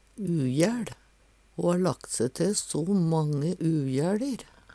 See also jæL (Veggli) Hør på dette ordet